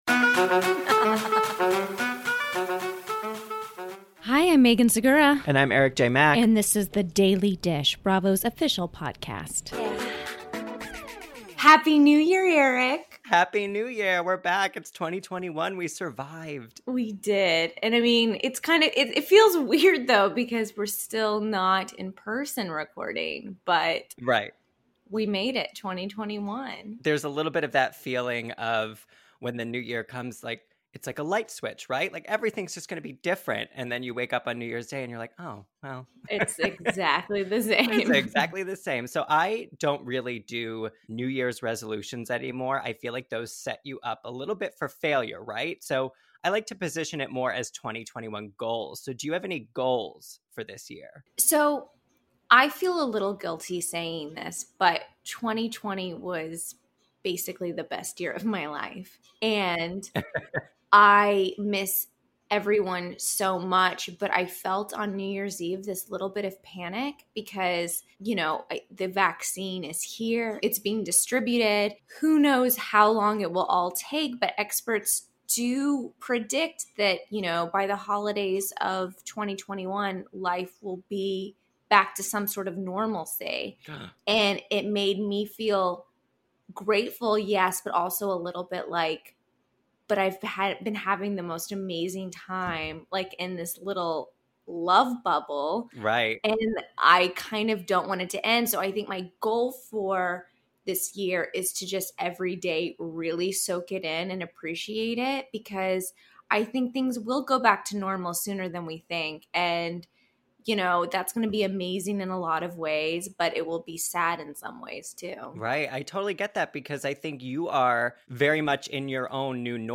Plus, The Real Housewives of Dallas is back with Season 5! Stephanie Hollman calls in to discuss Brandi Redmond’s baby news and her first impression of Tiffany Moon.